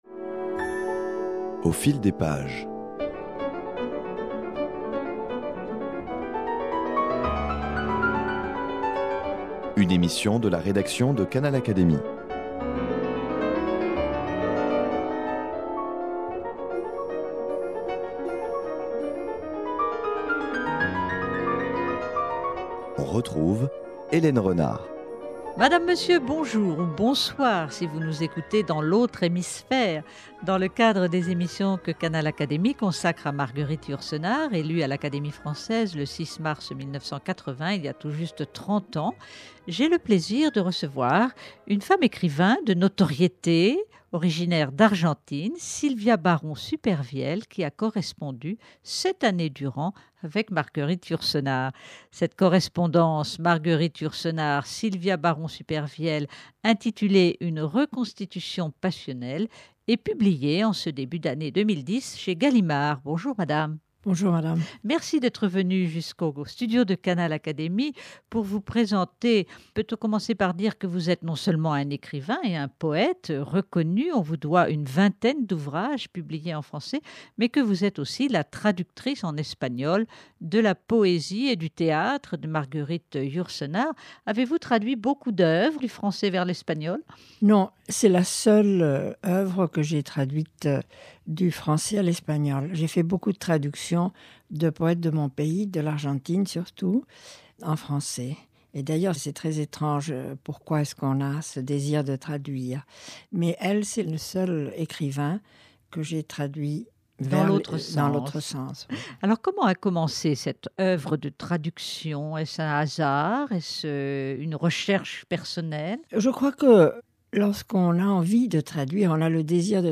Elle témoigne ici de cette inoubliable rencontre, dévoilant des aspects inattendus de la vie et de la personnalité de Marguerite Yourcenar.